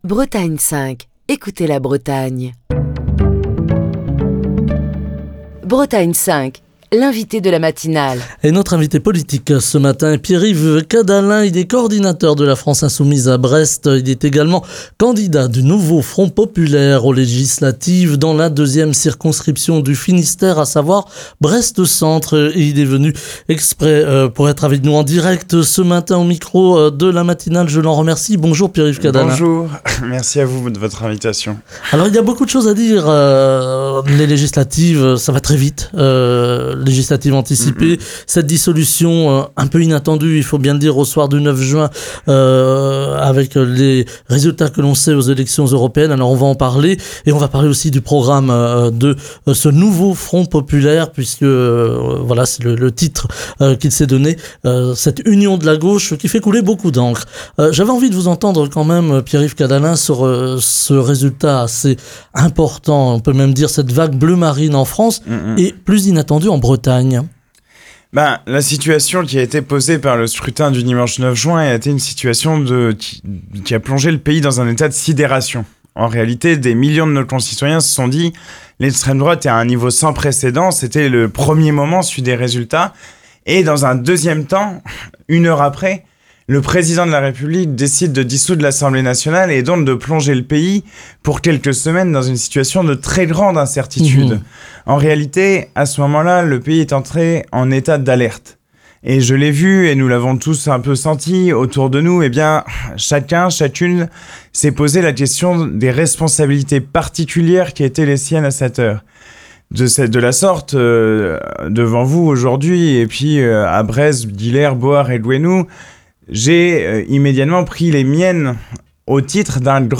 Pierre-Yves Cadalen, candidat du Nouveau Front Populaire - La France Insoumise, en lice pour les législatives dans la 2e circonscription du Finistère (Brest centre), est l'invité de la matinale de Bretagne 5. Pierre-Yves Cadalen analyse les résultats du Rassemblement National aux élections européennes du 9 juin. Il a exhorté les électeurs à se mobiliser contre l'extrême droite en votant pour le Nouveau Front Populaire, la coalition de gauche qui propose un programme résolument social et humaniste.